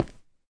stone2.mp3